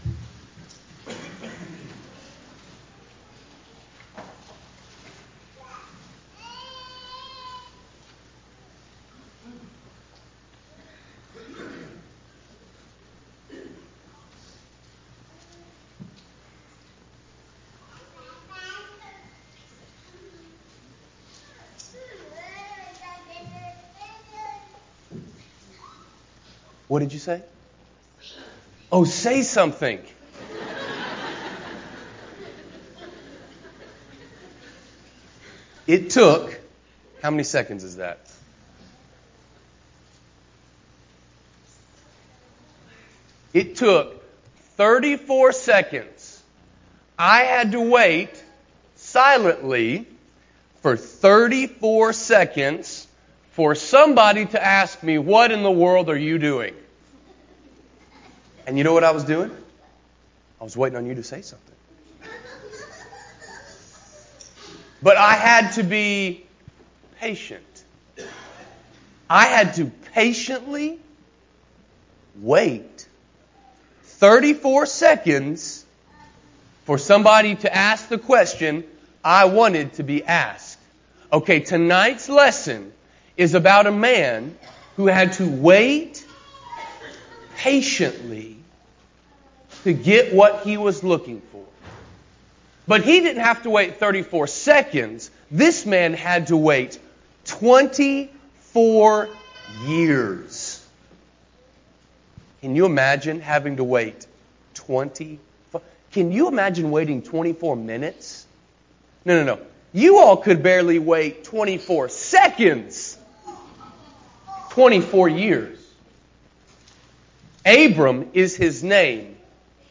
Patience (Kids lesson)